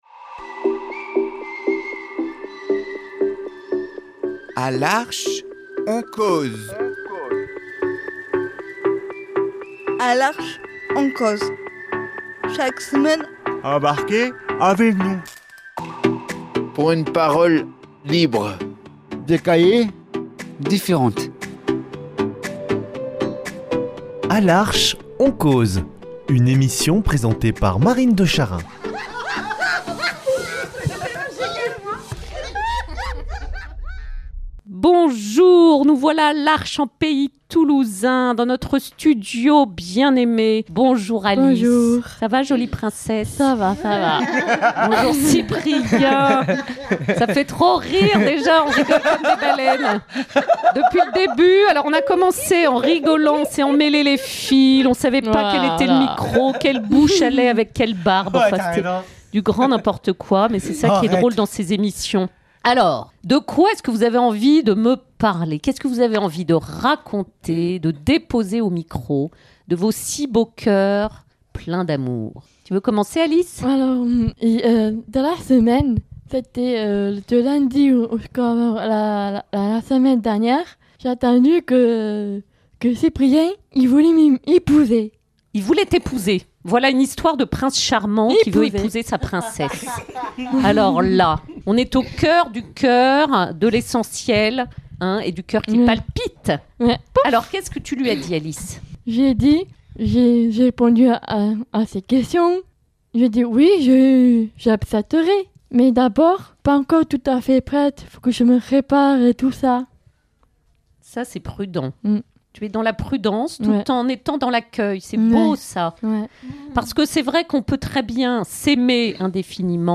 Conversation improvisée sur ce sujet tellement riche et remuant !